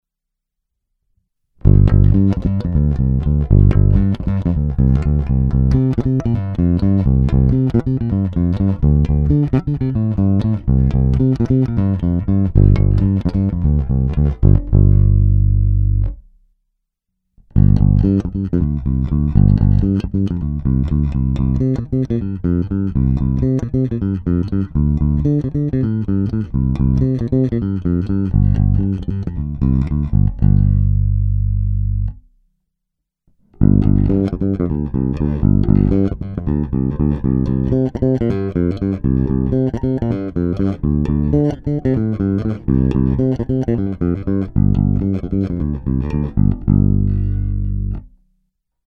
Ukázky jsou nahrány rovnou do zvukové karty a jen normalizovány.